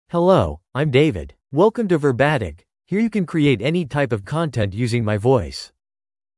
MaleEnglish (United States)
David is a male AI voice for English (United States).
Voice sample
David delivers clear pronunciation with authentic United States English intonation, making your content sound professionally produced.